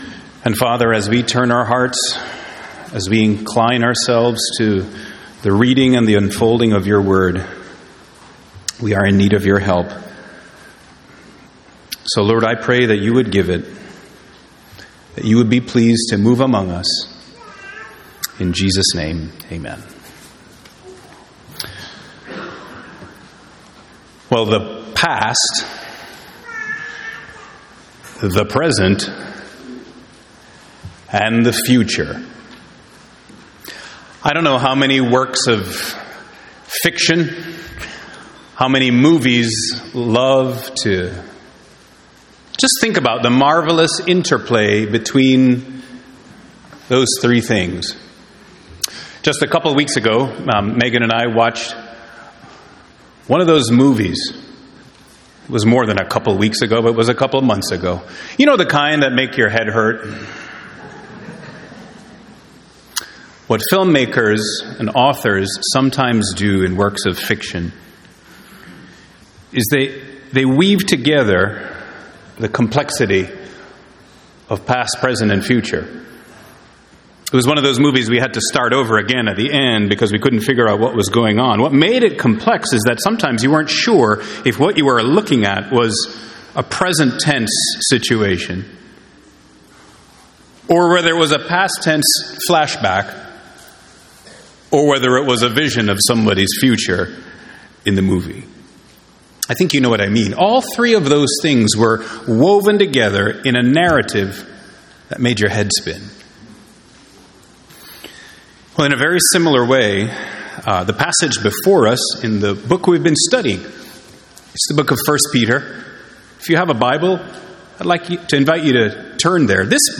Sermon Outline 1. Grief in the hand of God 2. Faith in the furnace of fire 3. Joy in the absence of sight